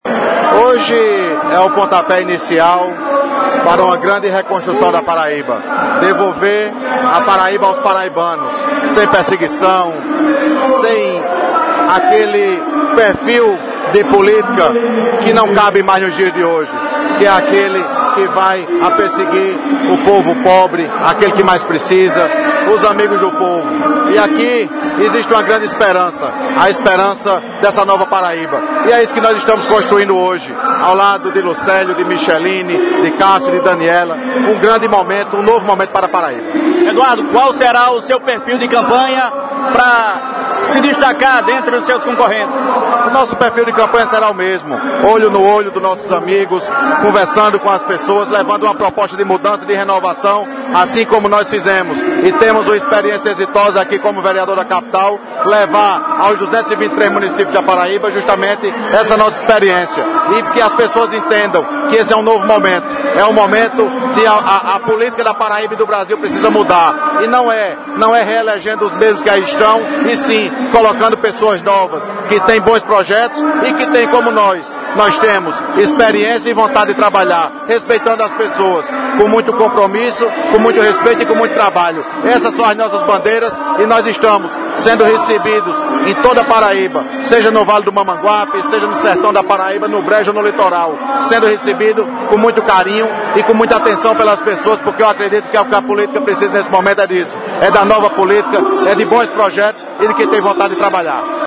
Na manhã deste domingo (5), o vereador de João Pessoa e candidato a deputado estadual, Eduardo Carneiro (PRTB), participou da Convenção do PV, que homologou o nome de Lucélio Cartaxo como candidato a Governador da Paraíba.